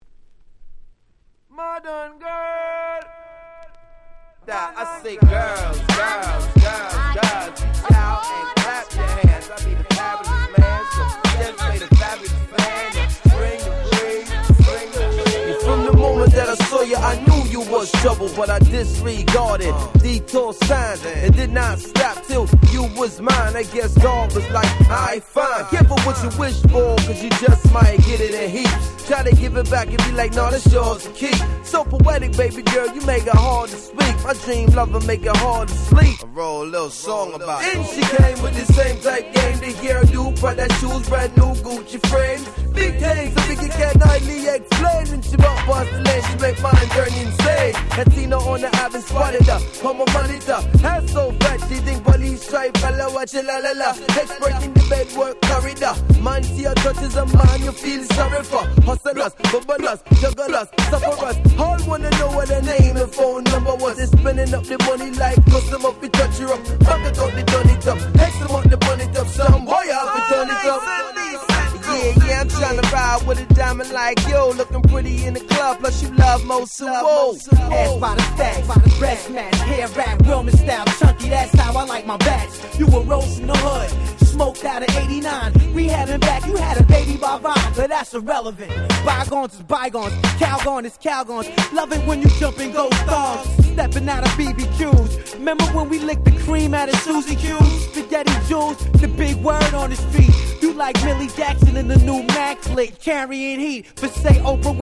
ブーンバップ Boom Bap